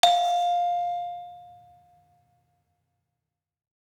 Gamelan Sound Bank
Saron-2-F4-f.wav